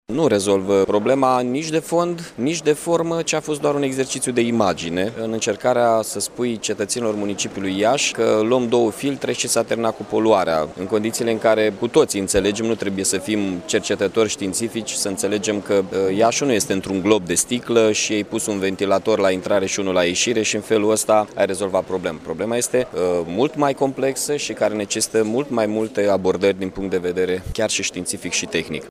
La rândul său, primarul Iaşului, Mihai Chirica, a delcarat că cele două staţii nu rezolvă problema de fond, iar  liberalii au transformat o chestiune de mediu într-o dispută politică.